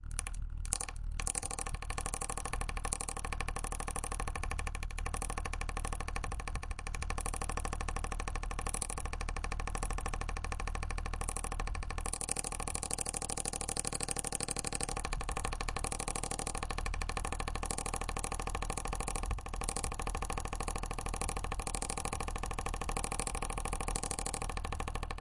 风扇 " 卡在风扇里的东西 01
描述：有些东西被风扇困住了。
Tag: 风能 呼吸机 风扇 空气 stucked 吹制 通风口